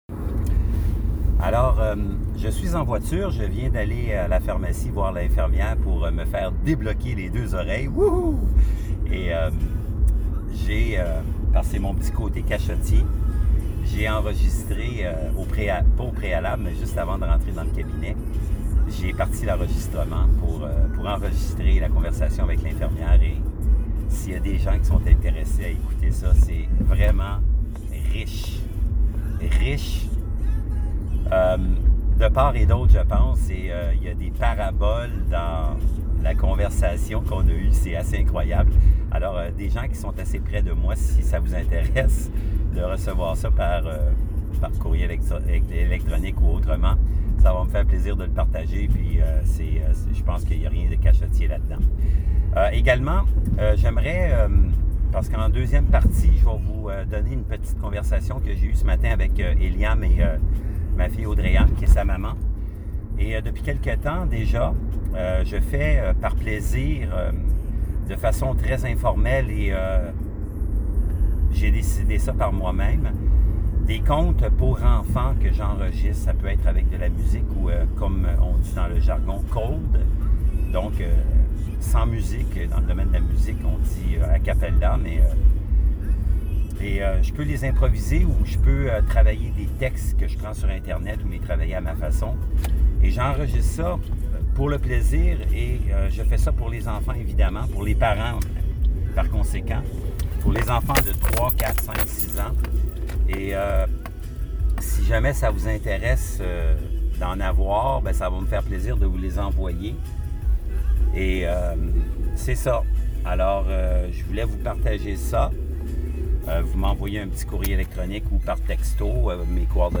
conte pour enfants…pour revasser!